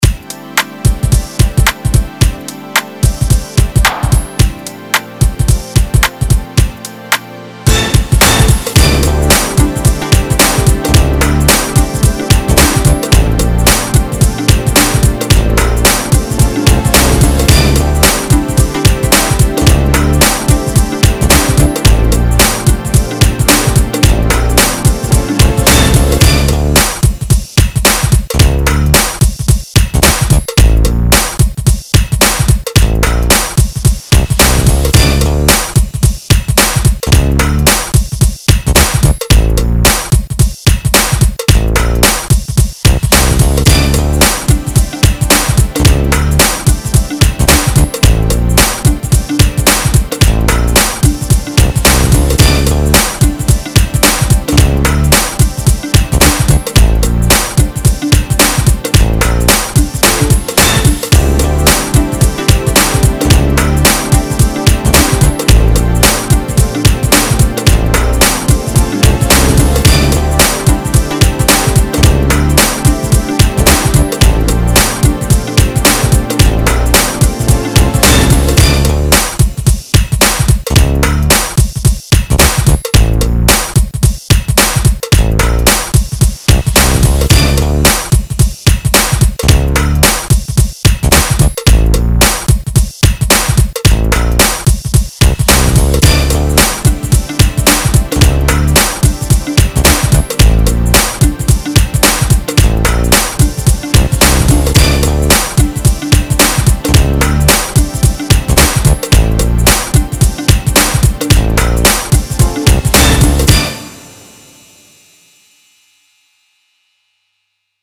80s, 90s, Dance, Hip Hop
Dm